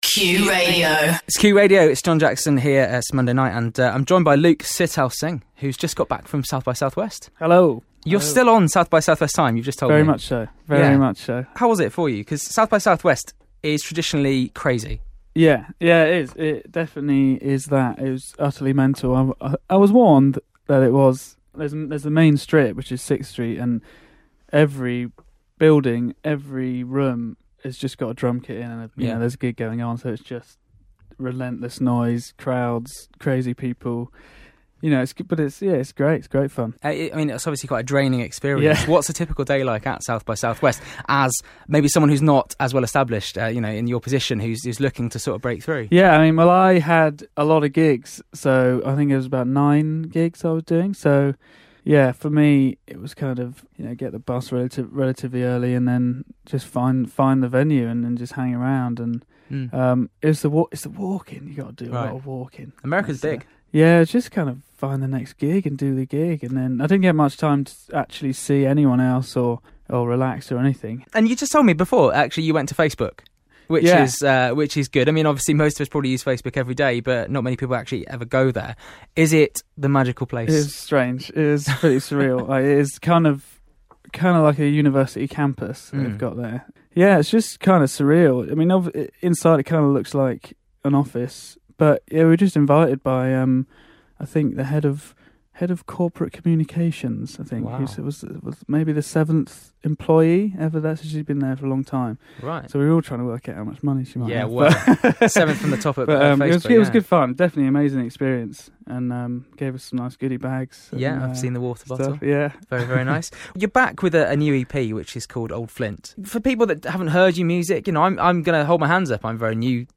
joins me in the studio